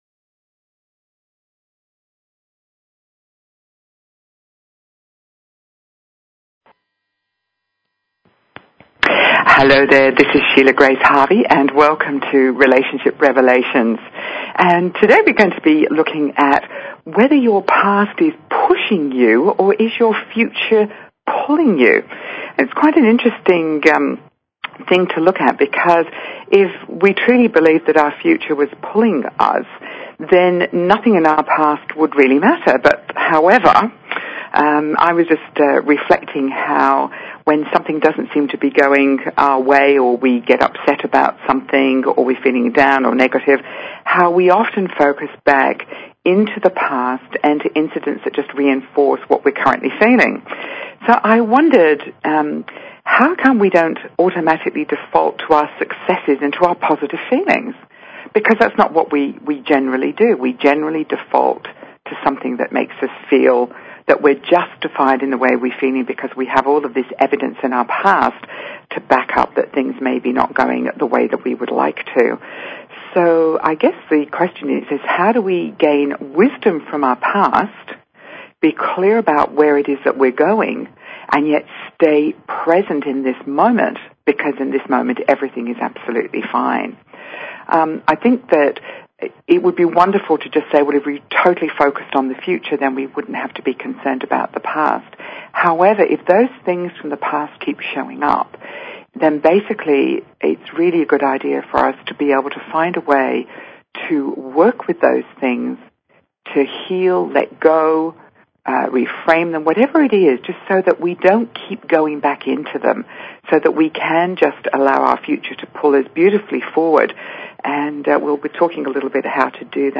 Talk Show Episode, Audio Podcast, Relationship_Revelations and Courtesy of BBS Radio on , show guests , about , categorized as